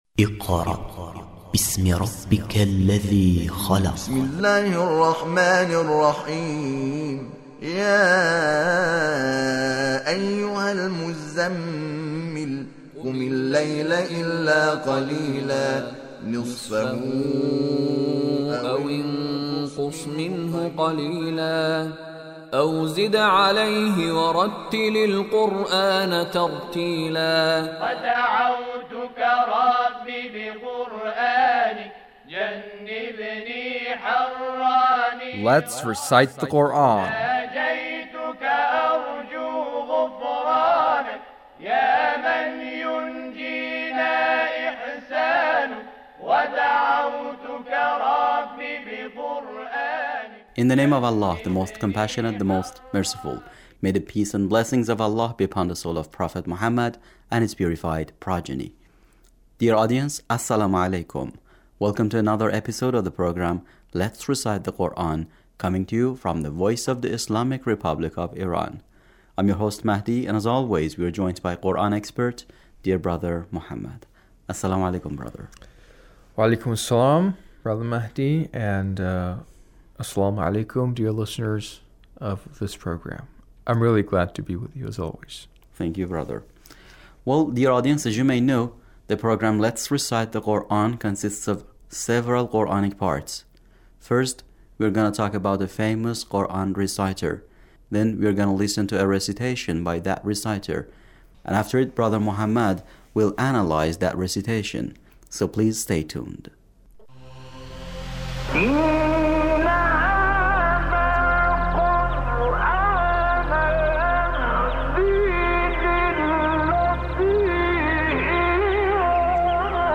Abul Ainain Shuaisha recitation